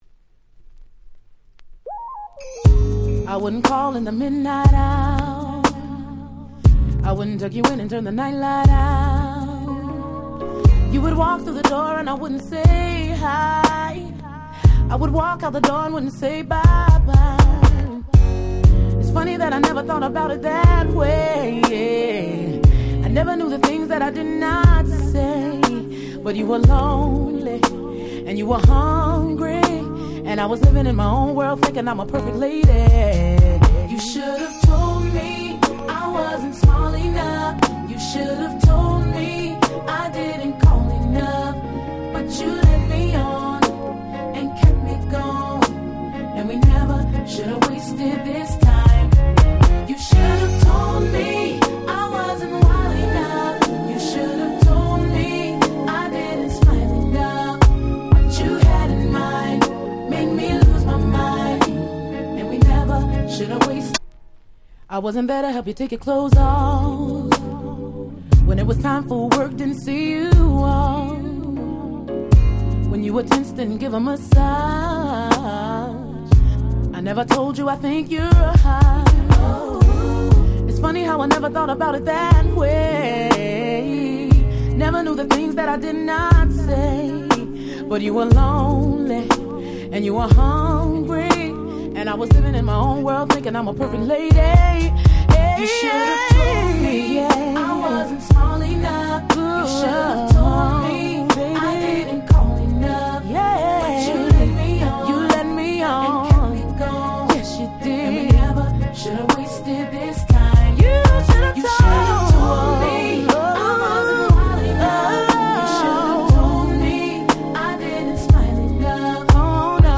00' Super Nice R&B / Slow Jam !!
最強、最高のSlow Jam。
スロウジャム バラード